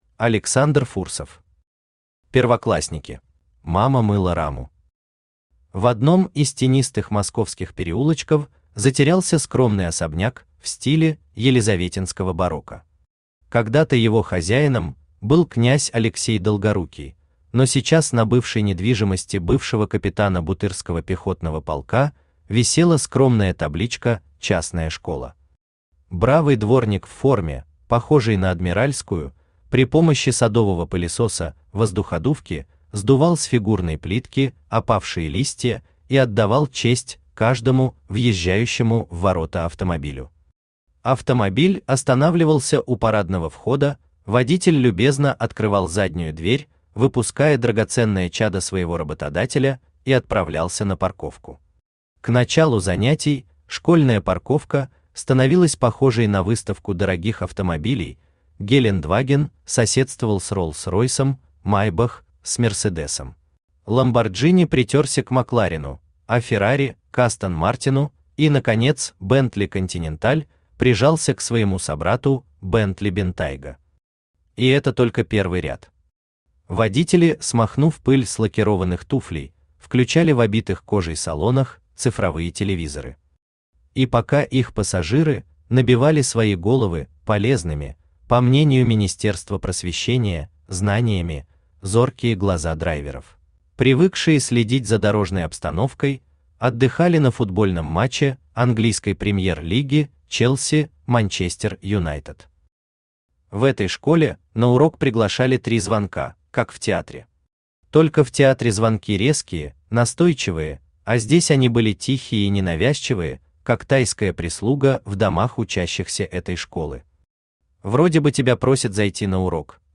Aудиокнига Первоклассники Автор Александр Иванович Фурсов Читает аудиокнигу Авточтец ЛитРес.